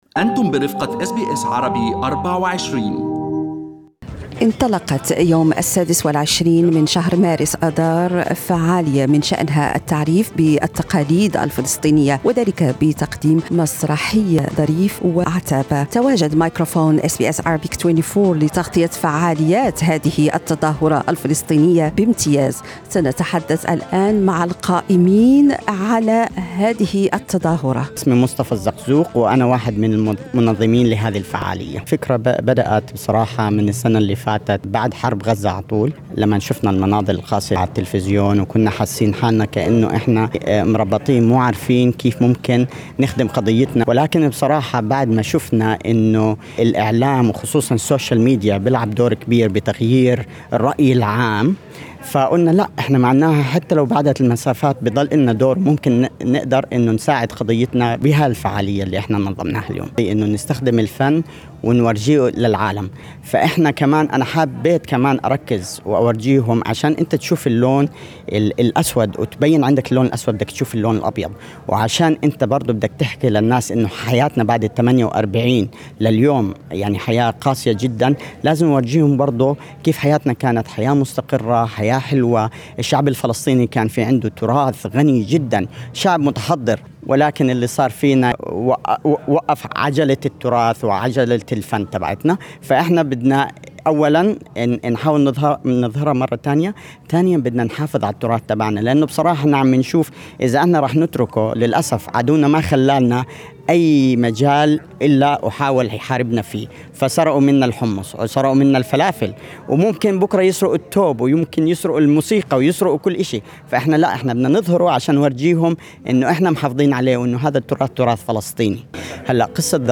تواجد مايكروفون إس بي إس عربي24 في مسرح Bryan Brown Theater & Function Centre يوم 26 مارس/ آذار2022 لنقل أجوائها. تحدثنا إلى القائمين على المسرحية من منسقين ومسؤولين وممثلين وراقصين، حيث أبدوا فخرهم بالمشاركة في المسرحية وأشاروا الى تنوع المجتمع الفلسطيني الذي يزخر بعادات وتقاليد مختلفة تتجلى في الحياة اليومية للمواطن الفلسطيني.